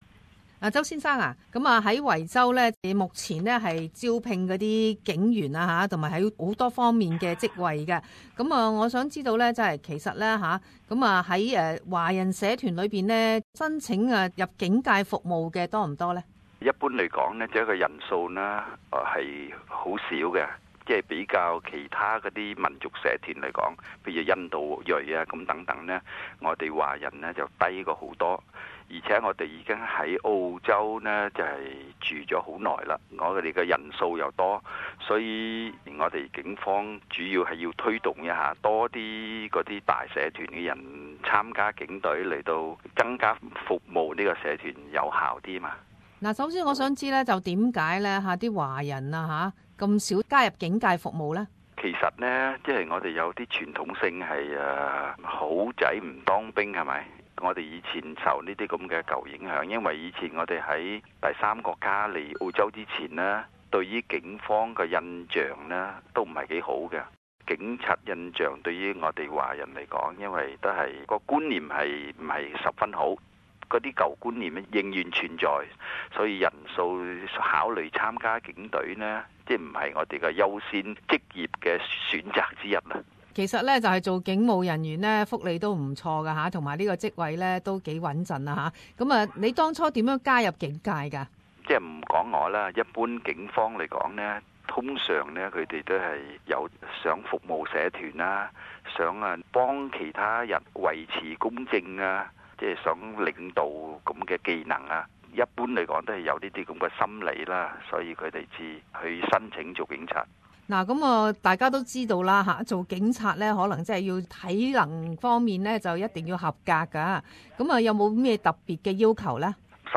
社团专访 - 维州警方招聘华裔警务人员